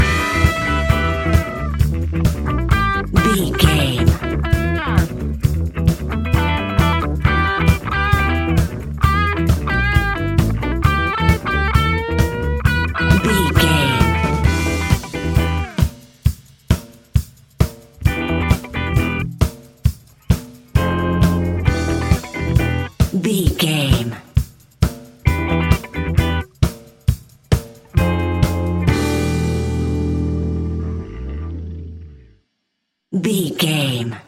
Ionian/Major
E♭
house
electro dance
synths
techno
trance
instrumentals